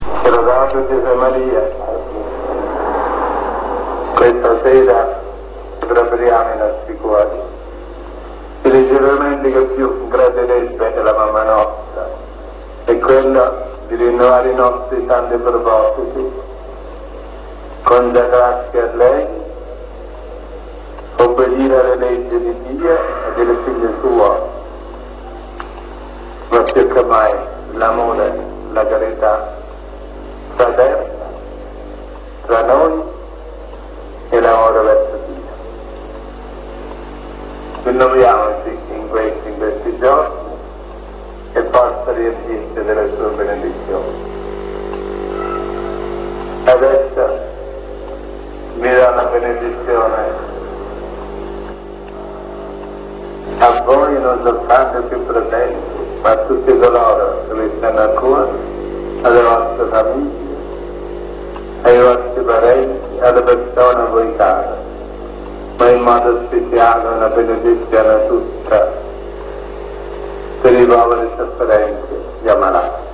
Qui sotto trovate quattro files della voce di Padre Pio.
Benedizione (146 Kb)